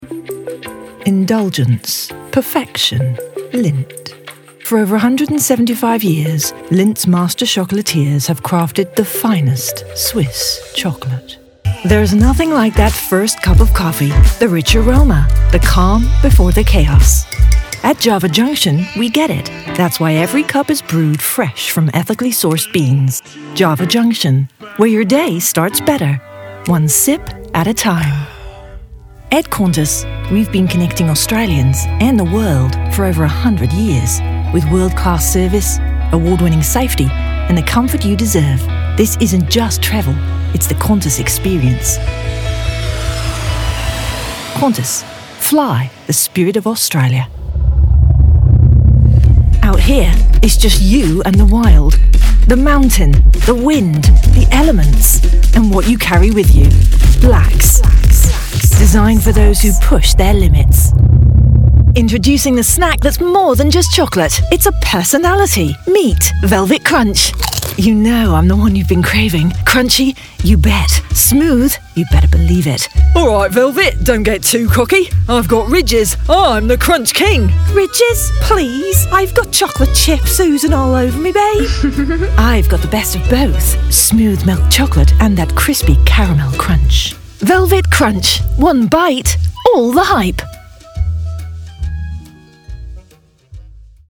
Commercial Reel New
English - British RP
Middle Aged